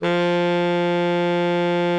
bari_sax_053.wav